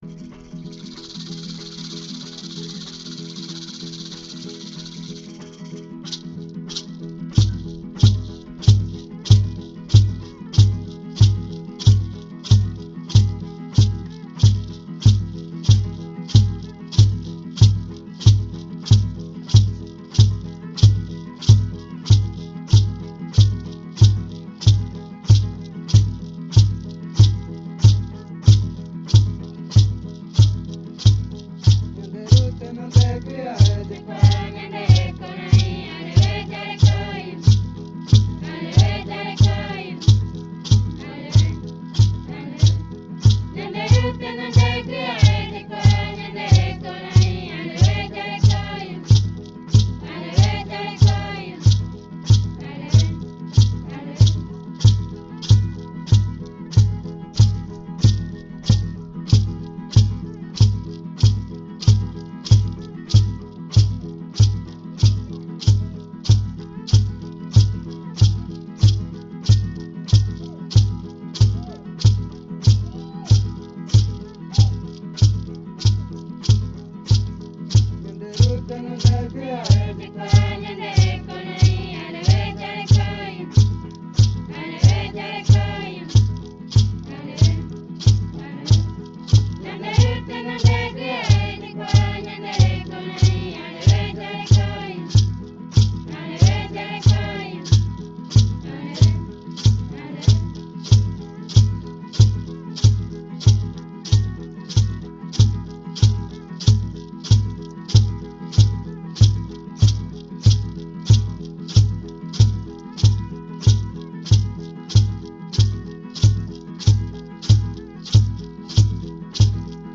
Nhãnderu Tenondaguiae – Coral Guarani Tenonderã